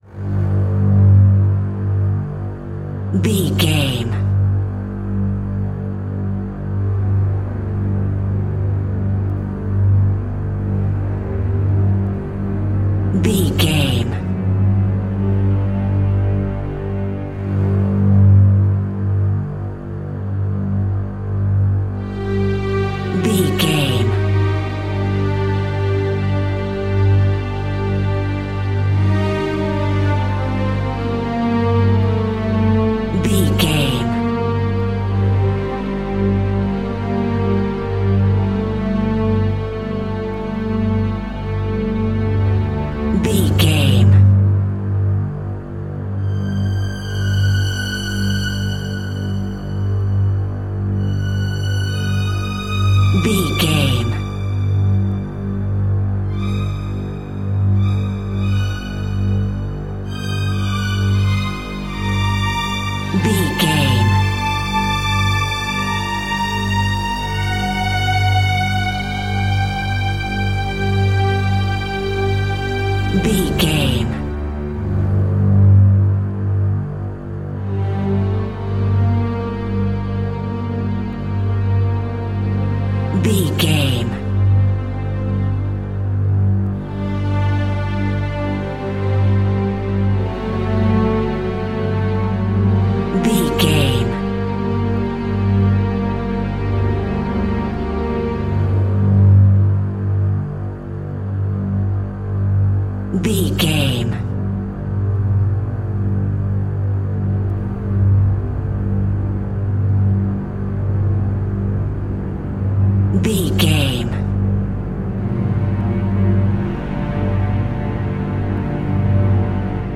Aeolian/Minor
scary
tension
ominous
dark
suspense
eerie
strings
horror
synth
ambience
pads